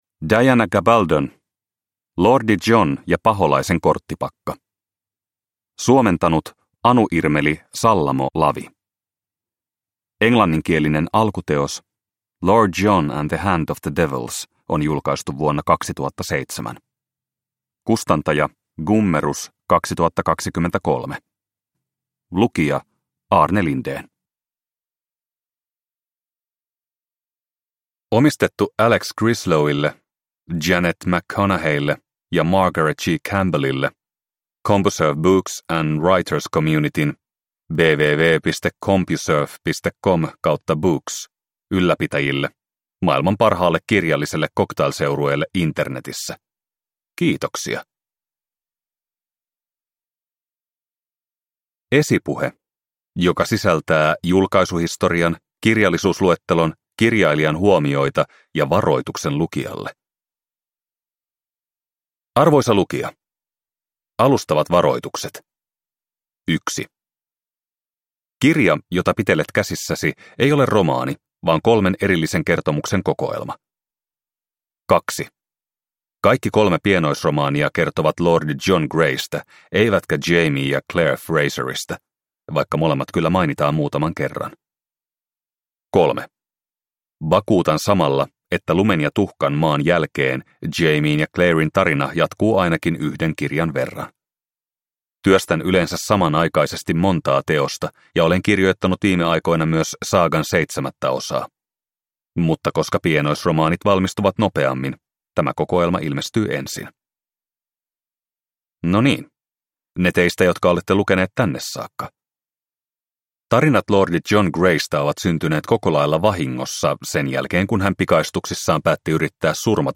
Lordi John ja paholaisen korttipakka – Ljudbok – Laddas ner